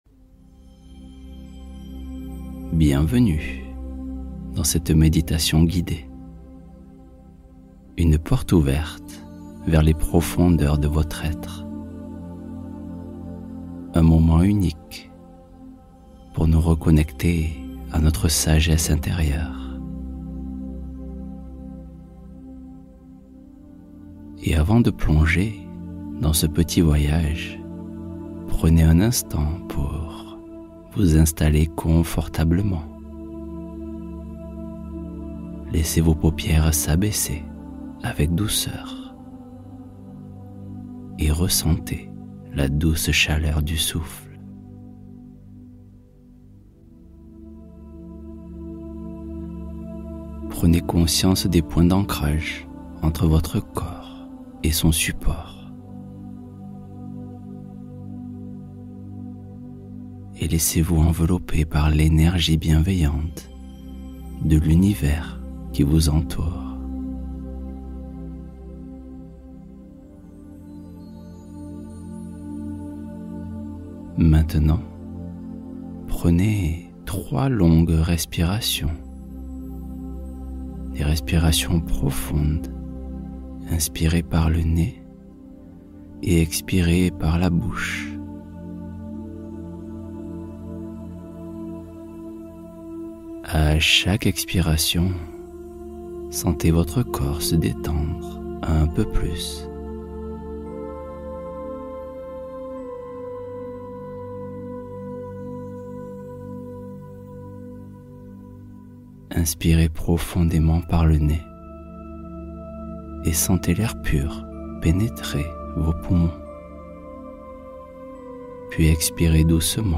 Sommeil transformateur : méditation du soir pour relâcher tout